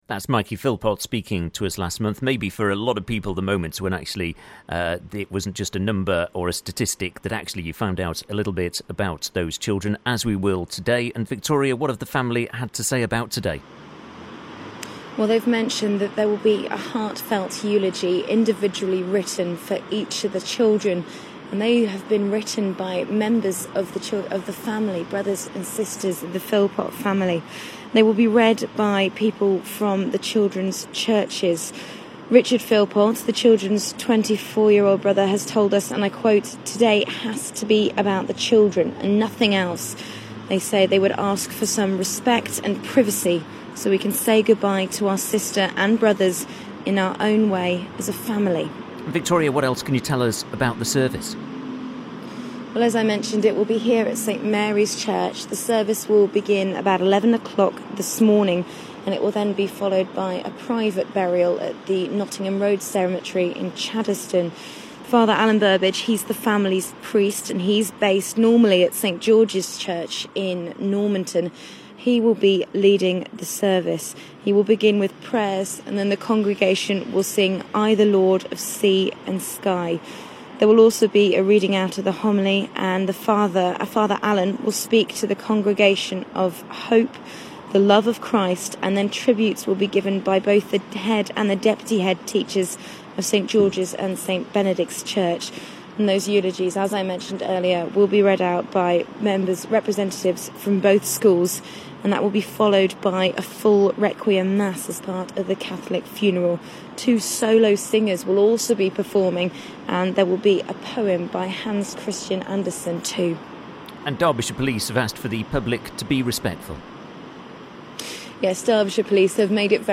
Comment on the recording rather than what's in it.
The funeral will take place today for the six children, killed in a house fire in Derby. The service is taking place at St Mary's Church on St Alkmunds Way in Derby.